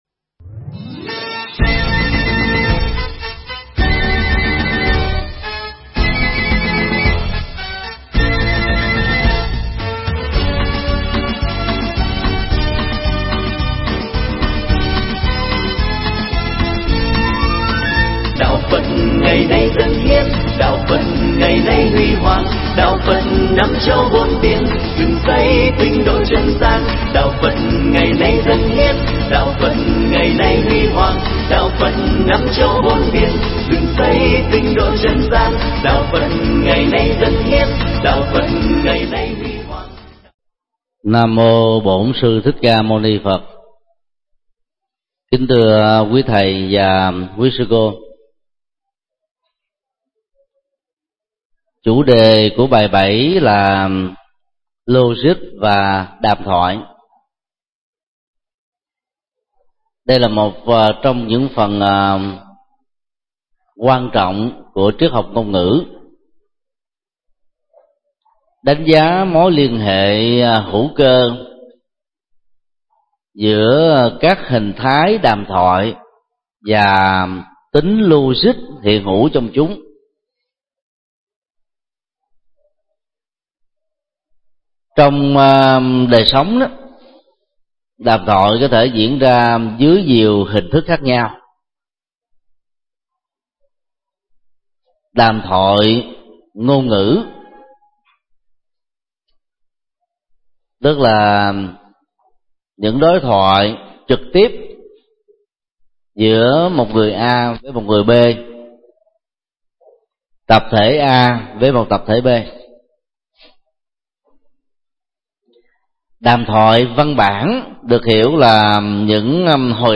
Tải mp3 Pháp Thoại Triết học ngôn ngữ Phật giáo 07
giảng tại Học viện Phật giáo Việt Nam tại TP. HCM